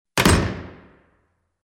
SFX – DOOR SLAM
SFX-DOOR-SLAM.mp3